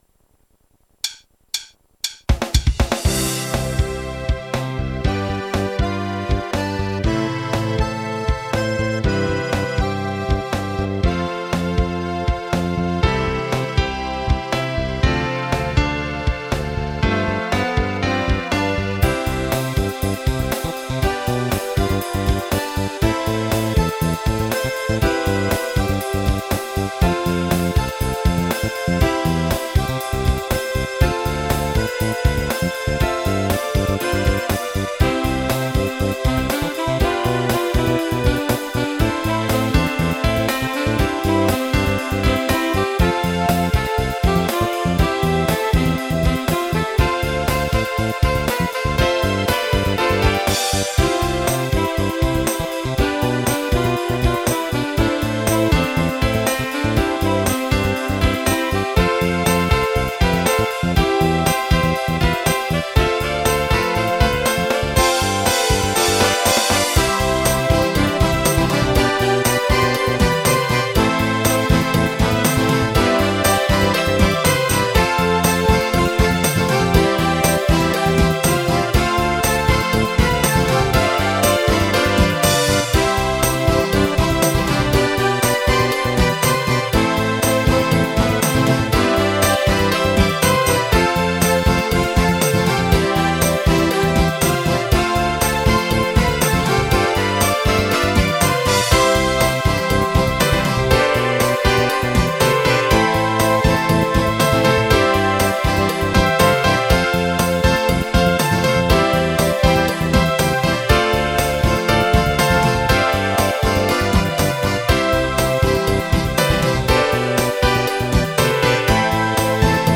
始めてＱＹ７０だけで作った曲です。